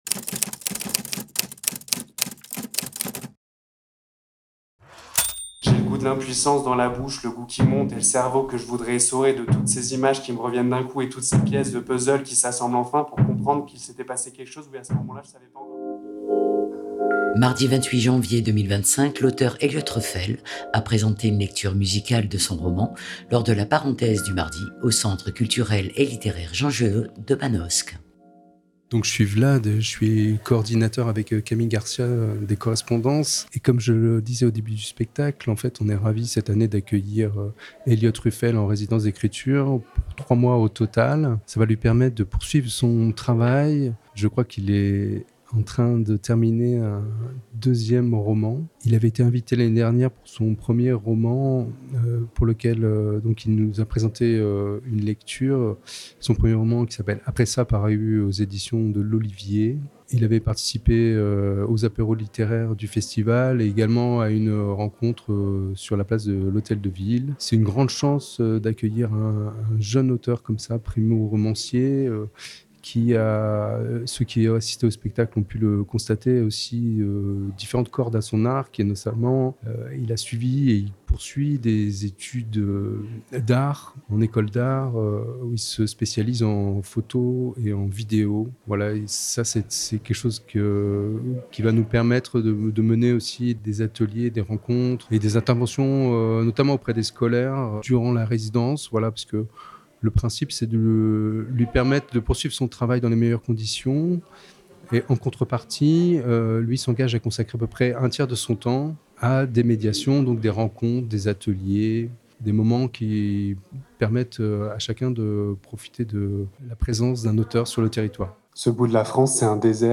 Au micro de Fréquence Mistral :
Le public...